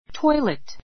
tɔ́ilit